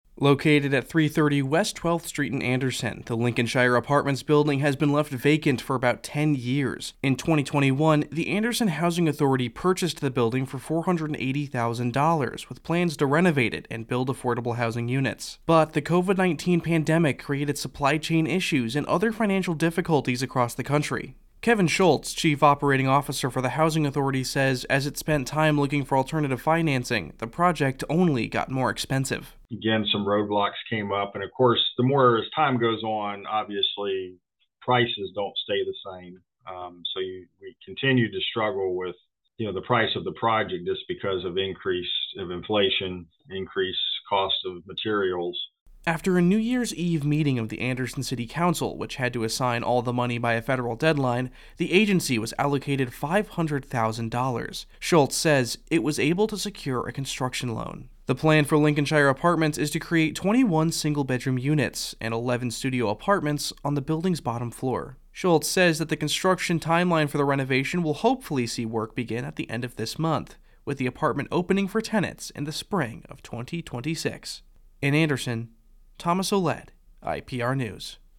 IPR News